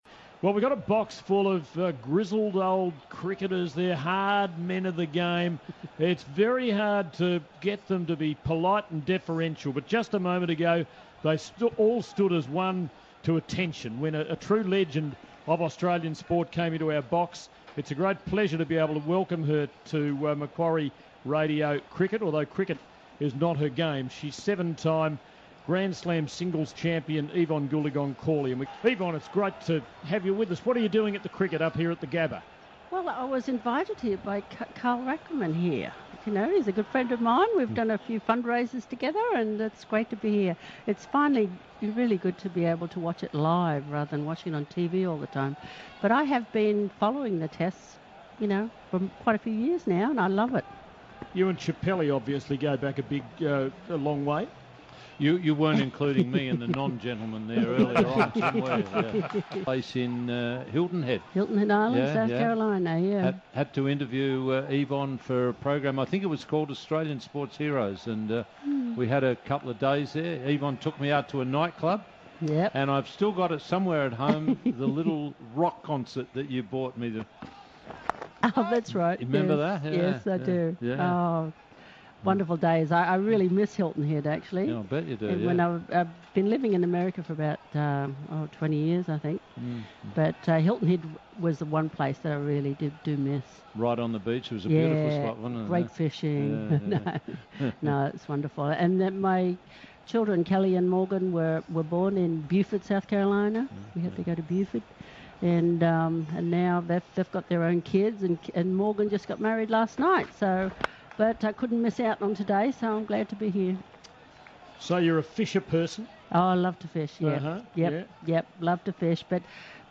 A true sporting legend paid a visit to the commentary box.